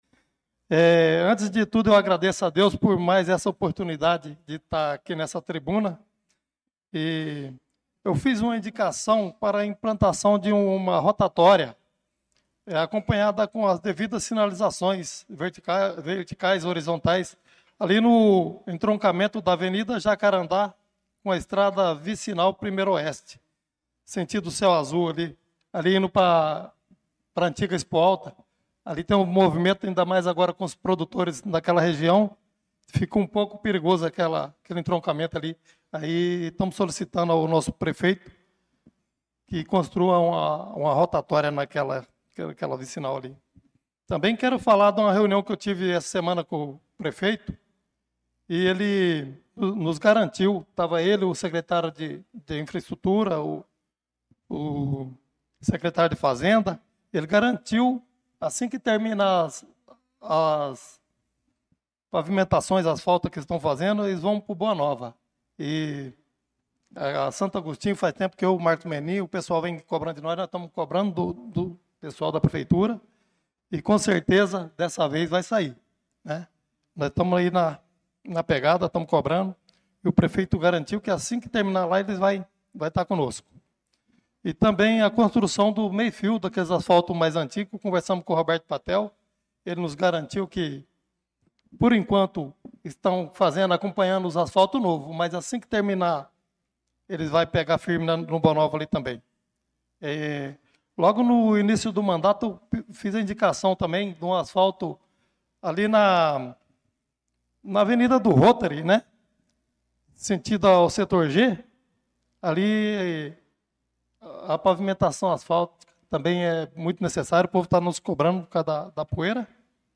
Pronunciamento do vereador Chicão Motocross na Sessão Ordinária do dia 25/08/2025.